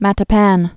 (mătə-păn), Cape